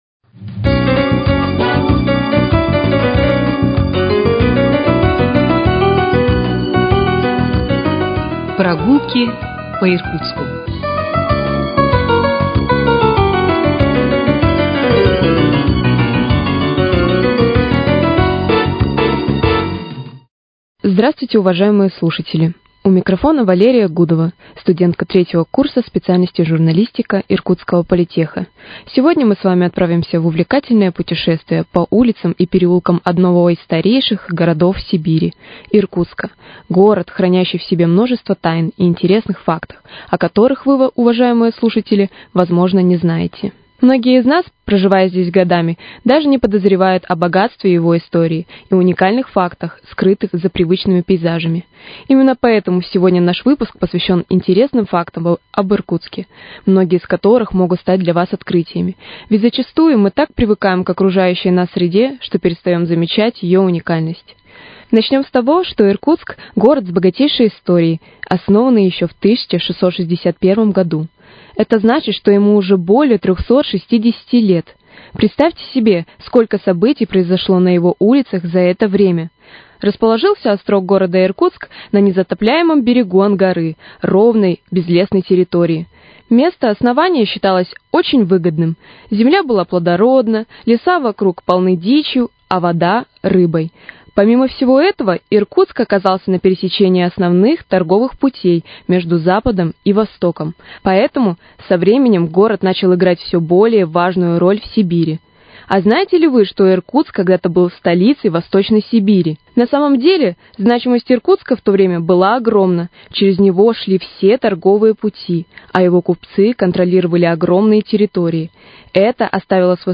В серии «Проба пера» сегодня ко Дню города по этой теме два материала начинающих журналистов - студентов 3 курса ИРНИТУ.